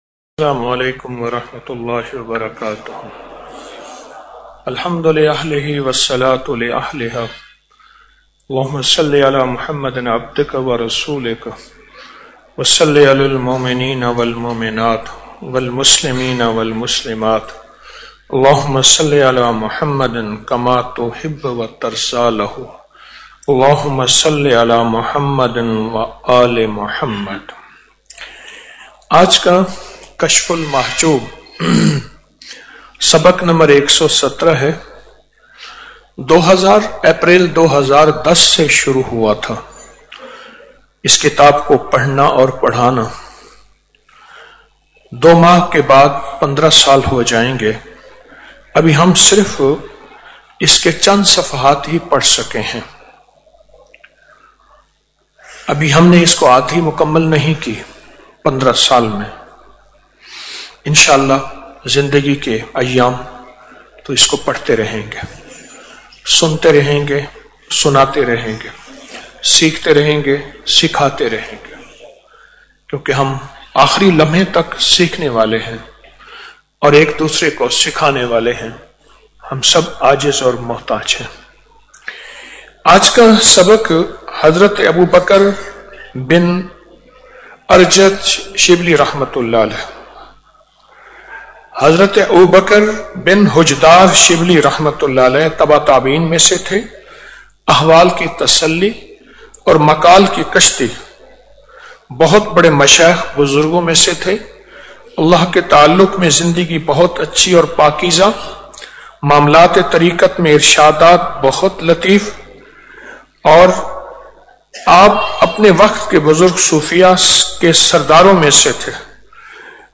Audio Speech - Halqa Kashf ul Majoob - 12 January 2025